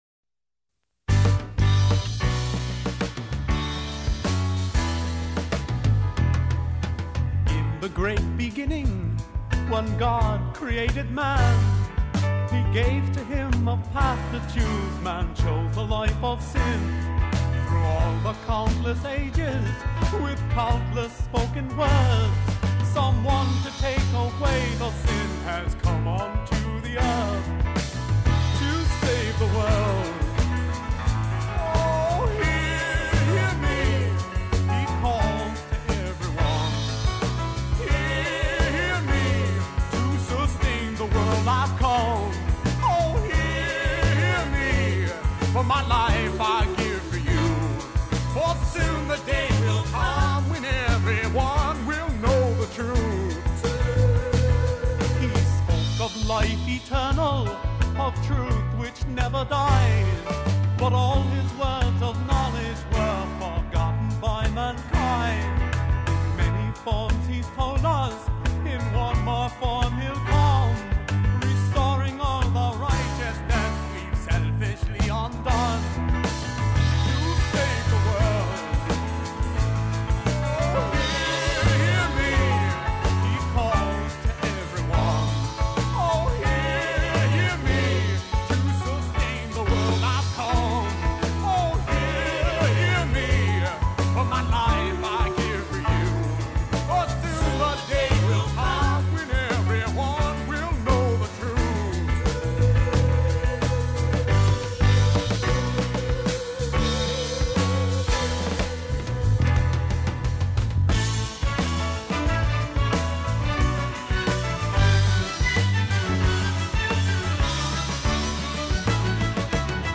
recorded with Nashville studio musicians 1973